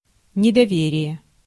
Ääntäminen
US : IPA : [(ˌ)dɪs.ˈtrəst] UK : IPA : /ˈdɪstɹʌst/ IPA : /ˈdɪstɹʊst/